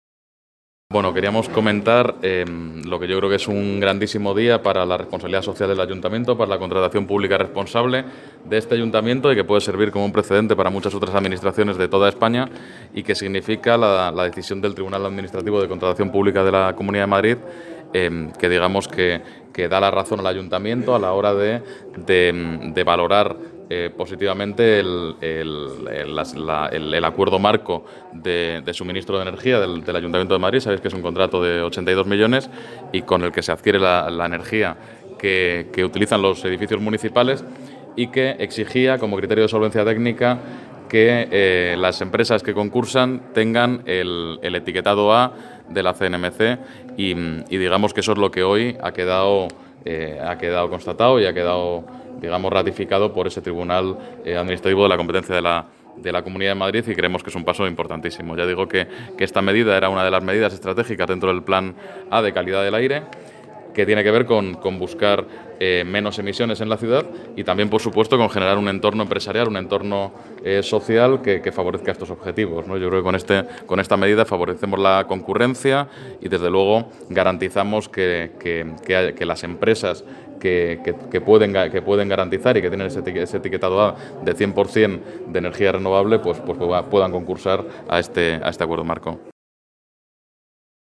Nueva ventana:Jorge García Castaño habla sobre la decisión del Tribunal Administrativo de Contratación Pública de la Comunidad de Madrid, que da la razón al Ayuntamiento